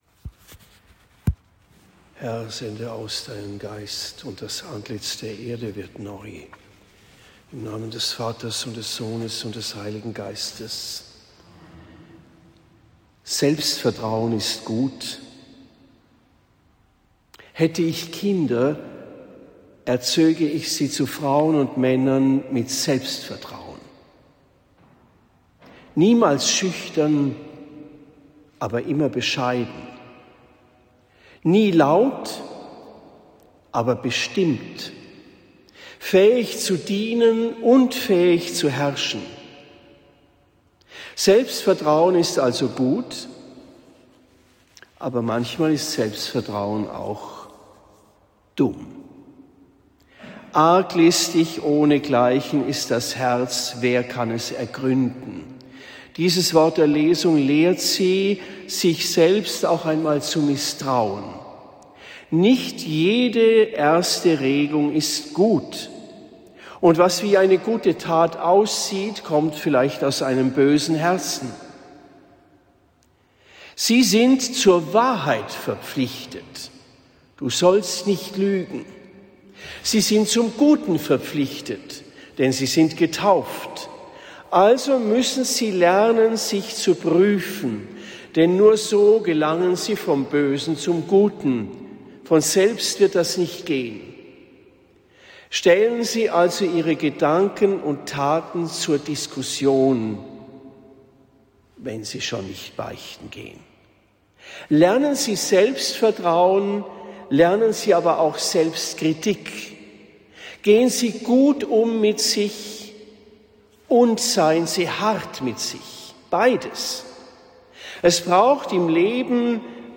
Predigt in Marktheidenfeld St.-Laurentius am 04. März 2026